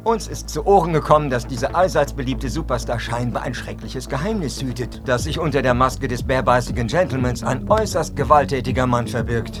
Baseball-Experte